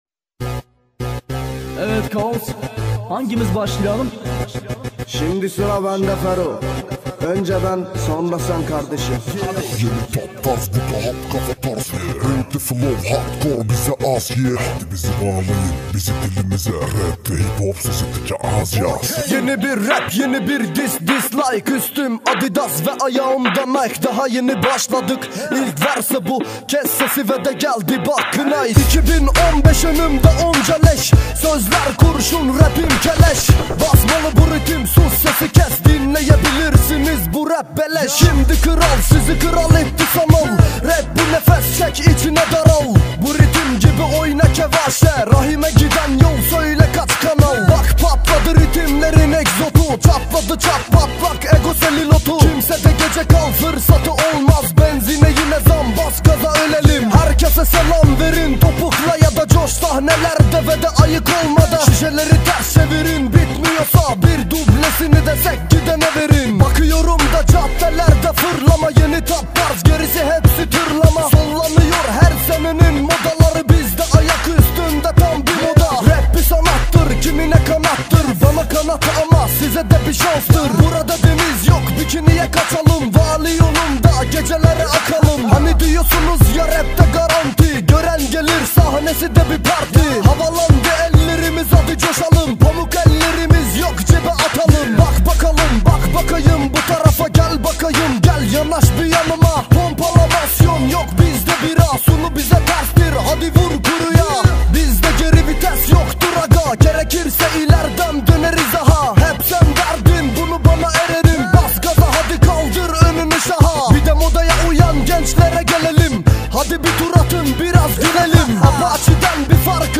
это динамичная композиция в жанре хип-хоп